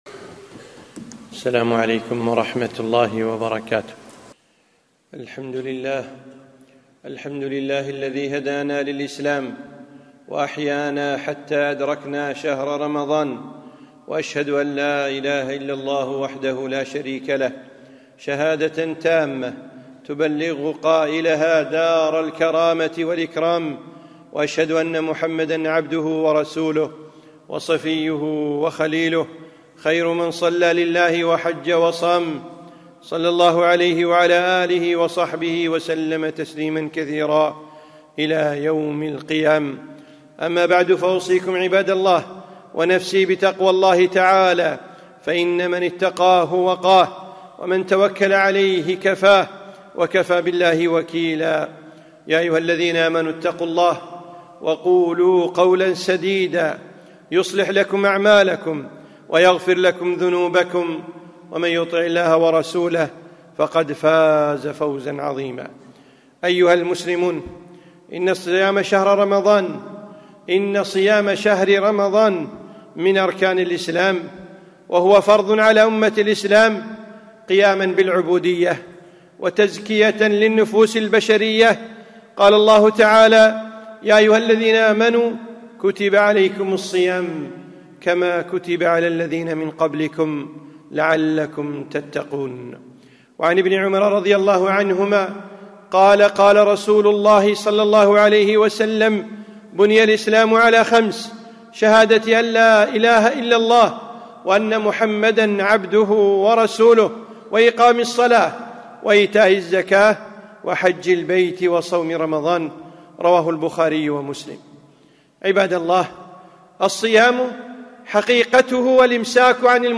خطبة - الصيام أحكام وآداب